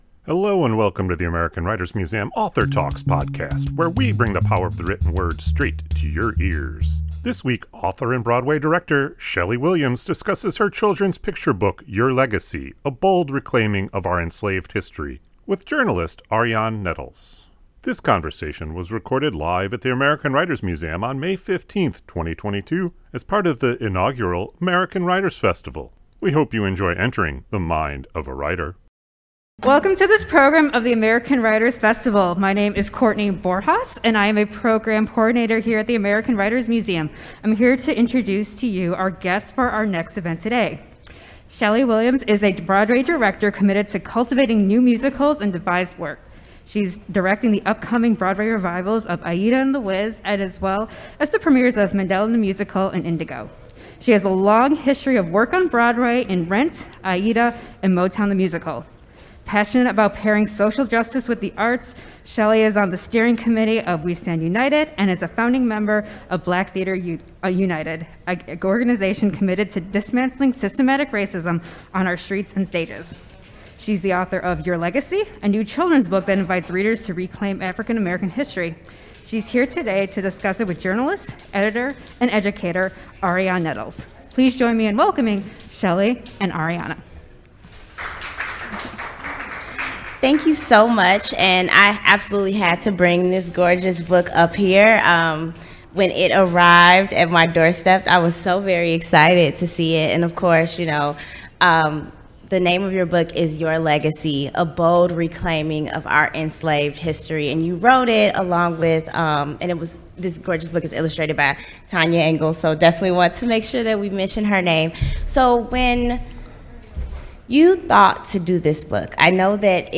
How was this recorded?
This conversation was recorded live at the American Writers Museum on May 15, 2022 as part of the inaugural American Writers Festival.